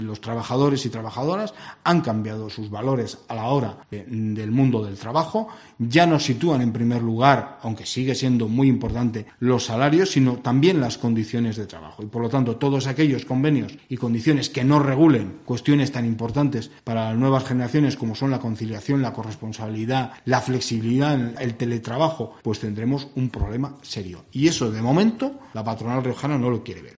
En una rueda informativa